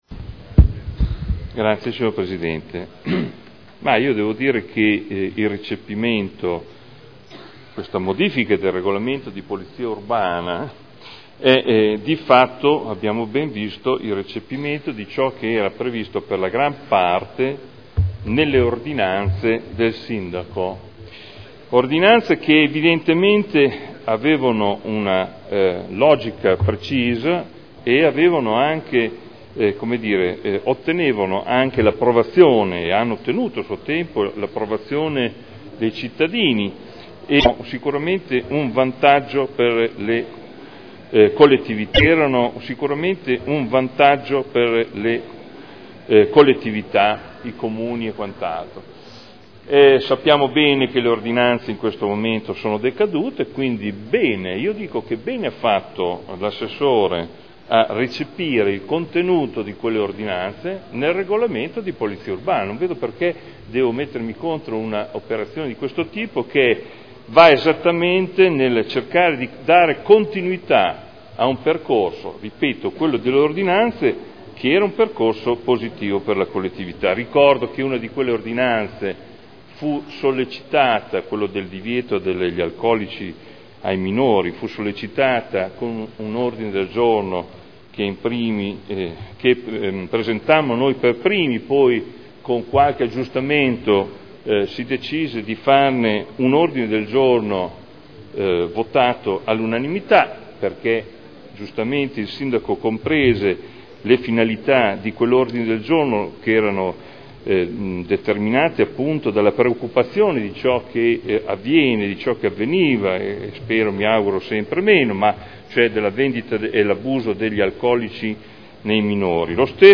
Seduta del 20/06/2011. Modifiche al Regolamento di Polizia Urbana approvato con deliberazione del Consiglio comunale n. 13 dell’11.2.2002 Dibattito